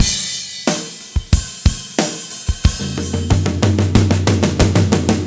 09 rhdrm91tom.wav